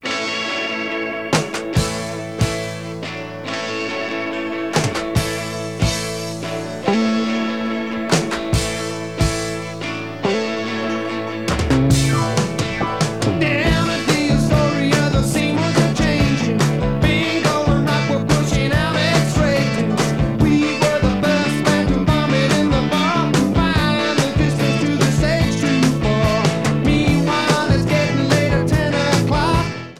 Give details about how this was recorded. Sound Samples (All Tracks In Stereo Except Where Noted) demo version